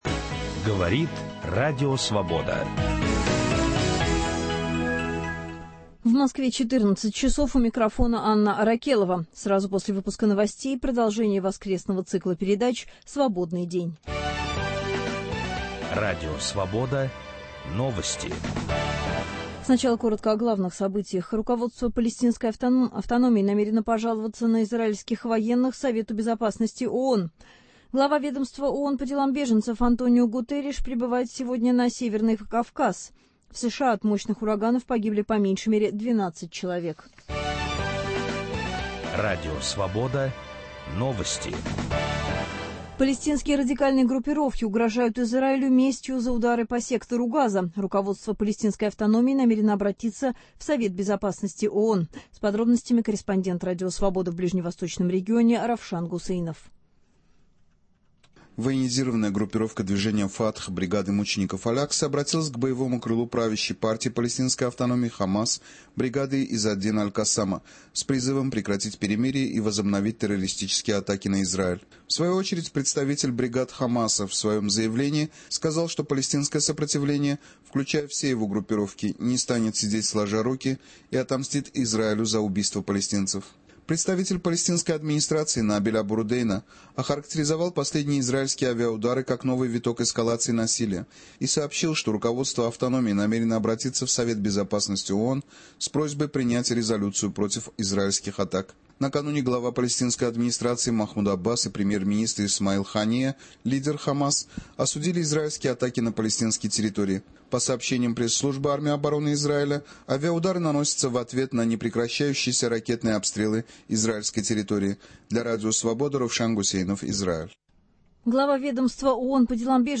В нашей студии Народный артист СССР, председатель Комитета по культуре Государственной Думы Иосиф Кобзон.